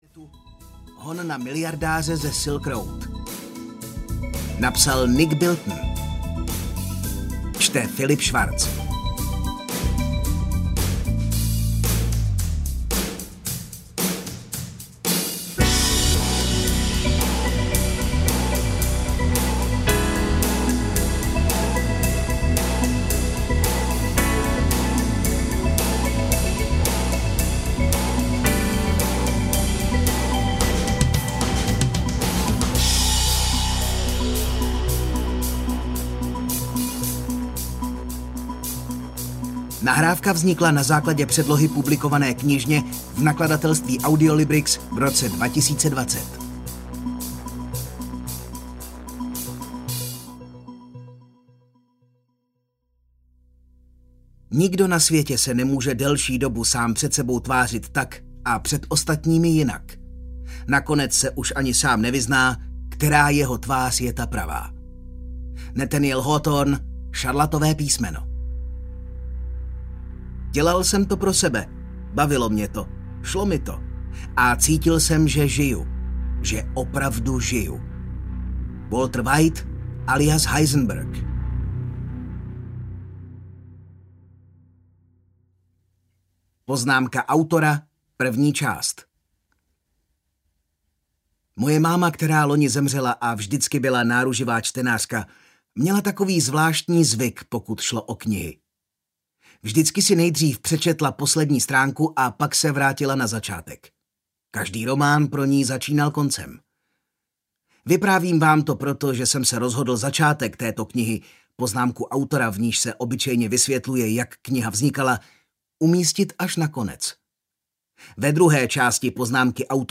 Překupník na darknetu audiokniha
Ukázka z knihy